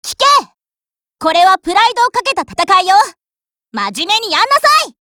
Cv-20213_warcry.mp3